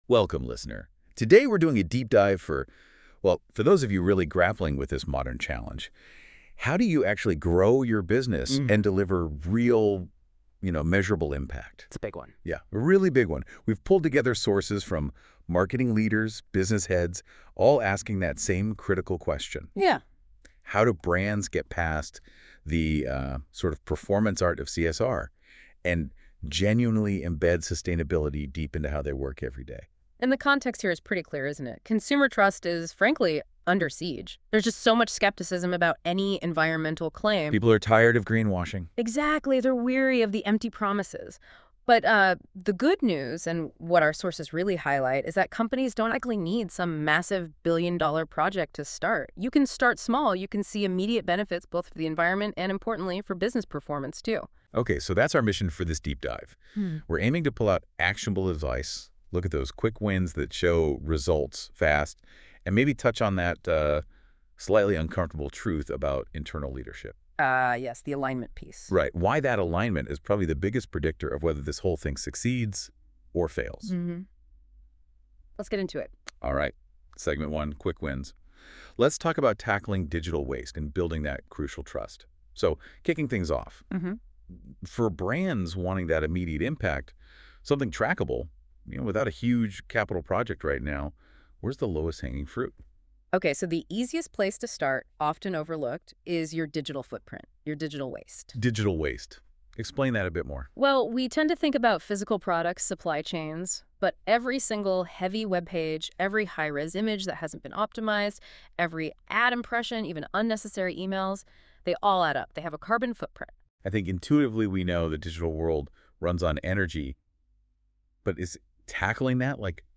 And for those who prefer listening over reading, we’ve captured the highlights in a 10-minute Notebook LM podcast episode: From Greenwashing to Growth: How to Embed Sustainability for Fast Wins and Unshakeable Client Trust.